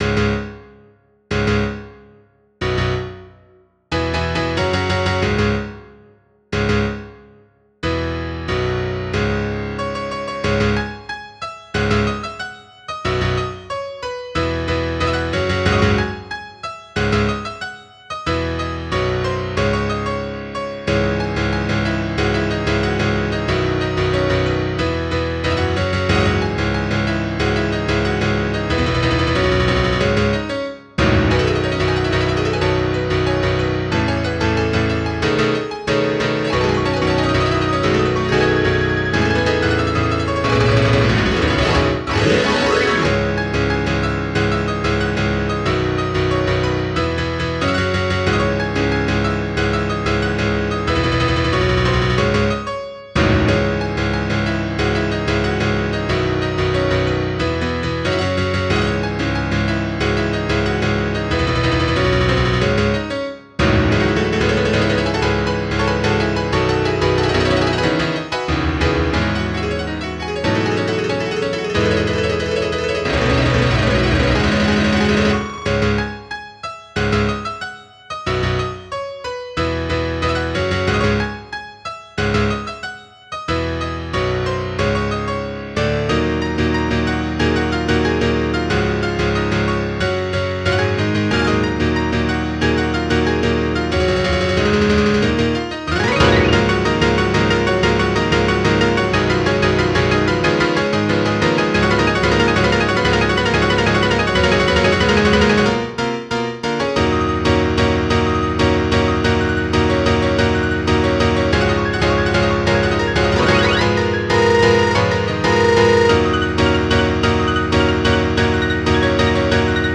Piano for people with 100 fingers File Listing Dueling_Dragons.mscz Dueling_Dragons.wav Erraticism.mscz Erraticism.wav auto_piano_cs_minor.mscz auto_piano_cs_minor.wav Go up to parent folder (/fun/music/scores)
auto_piano_cs_minor.wav